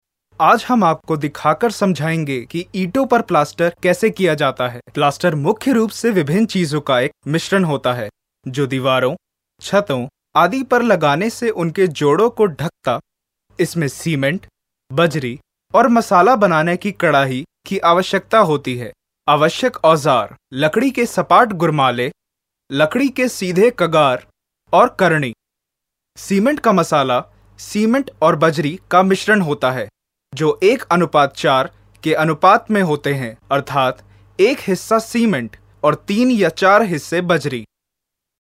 印度语男4
印度语男4_外语_小语种_vo.mp3